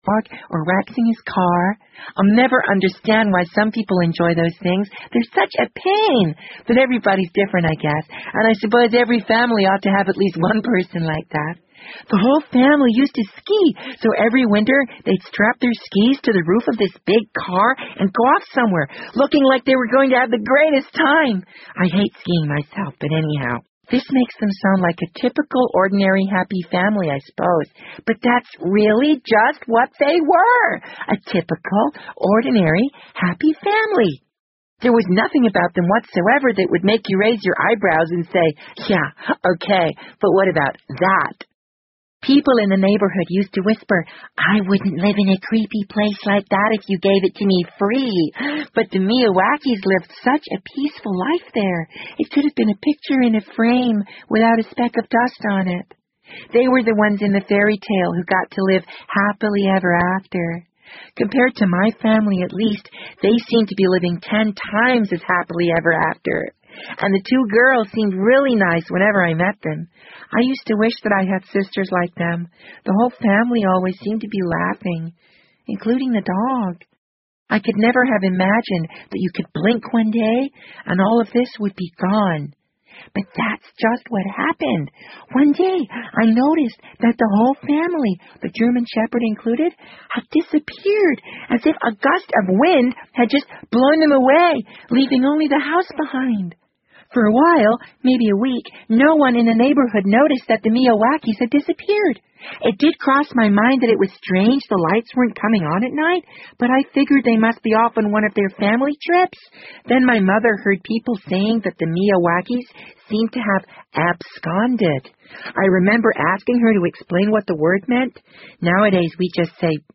BBC英文广播剧在线听 The Wind Up Bird 013 - 16 听力文件下载—在线英语听力室